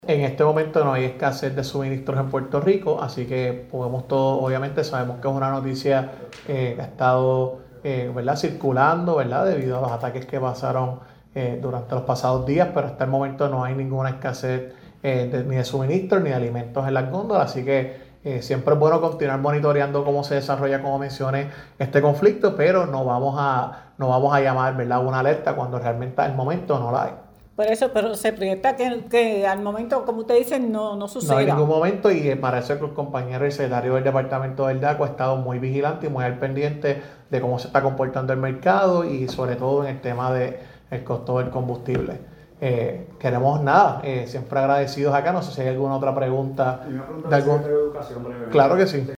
No hay escasez ni de gasolina ni de suministros a raíz de los ataques en medio oriente, aclara Secretario de Asuntos Públicos (sonido)